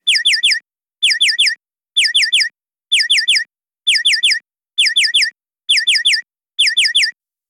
Efecto semáforo en ámbar indicando peligro próximo cambio a rojo
Sonidos: Ciudad